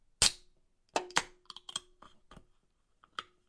开罐
描述：锡/铝可以打开。发出声音。